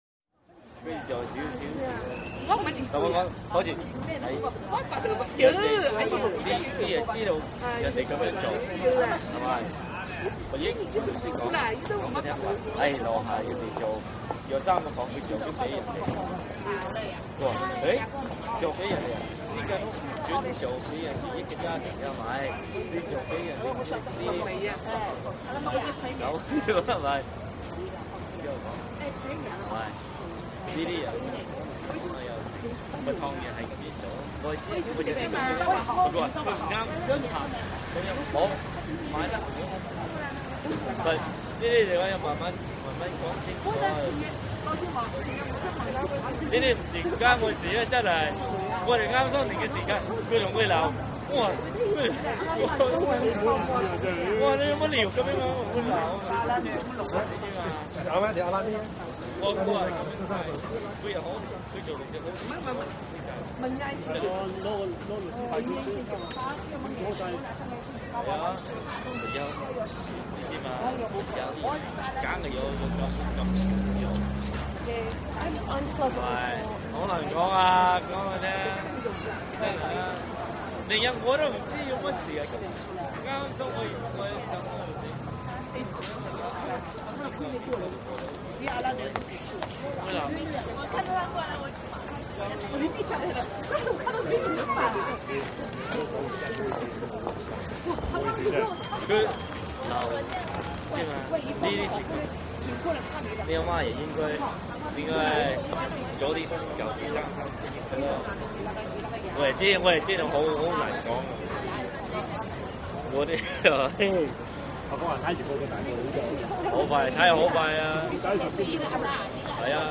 Chinatown Street Walla; Two Men And Three Women Close Chinese Walla With Medium To Distant Traffic Roar And Pedestrian Movement, A Few Distant Horn Honks.